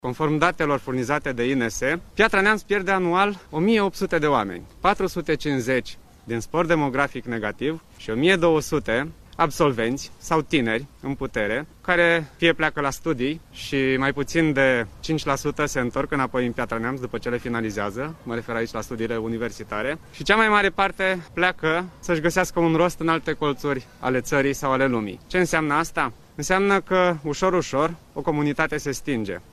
candidat PNL la Primăria Piatra-Neamţ a declarat, într-o conferinţă de presă, că trebuie stopat exodul tinerilor în afara ţării: